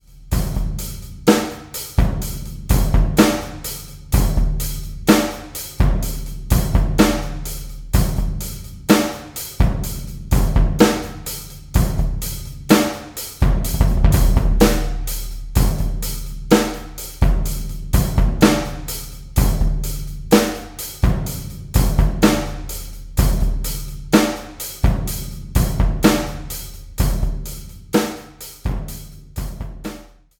Your kick drum sounds quite slack.